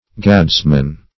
Gadsman \Gads"man\, n. One who uses a gad or goad in driving.